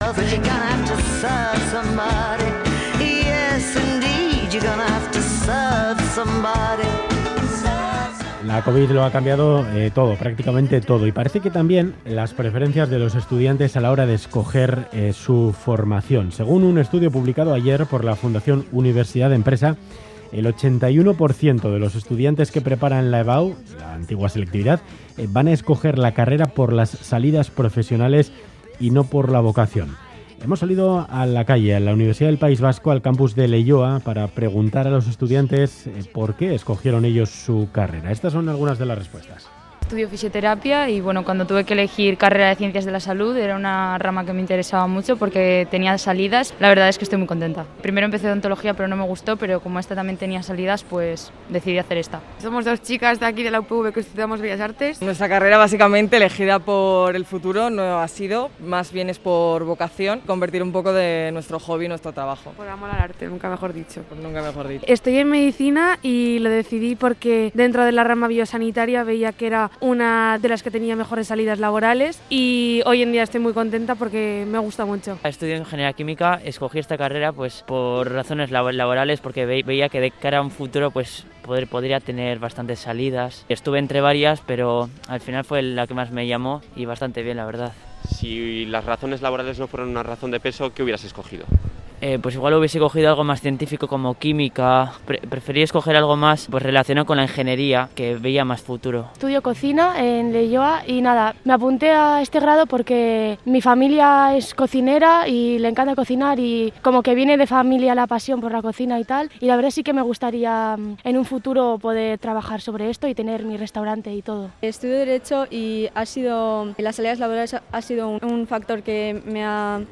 Audio: Tertulia sobre razones se tienen en cuenta a la hora de elegir carrera